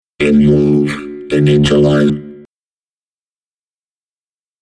starting-mouth.mp3